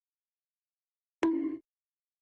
Coconut Sound Effect
Coconut Sound – a hollow clopping noise mimicking horse hooves, used in memes for comedic or absurd sound effects.